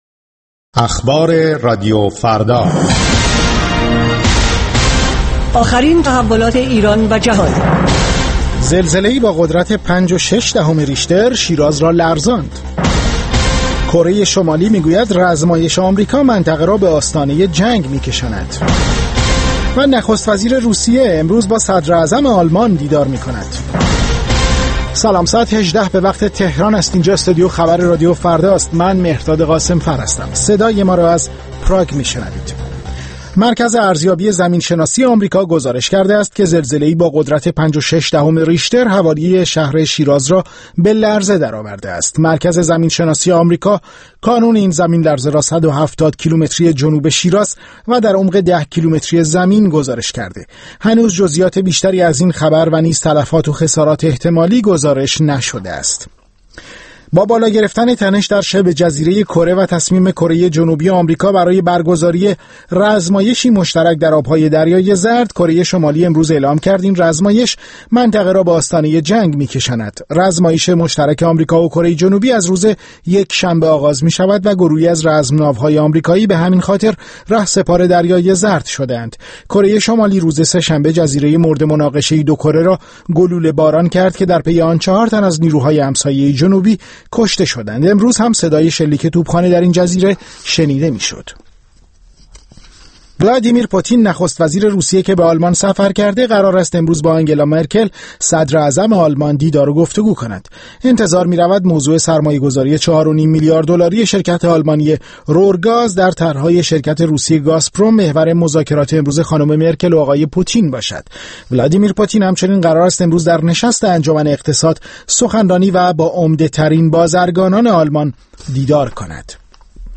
در روز جهانی نفی خشونت علیه زنان، ساعت ششم با تماس‌های مستقیم تلفنی شما، به مسئله خشونت علیه زنان در ایران می‌پردازد.